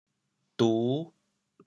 潮阳拼音“du5”的详细信息
国际音标 [tu]
du5.mp3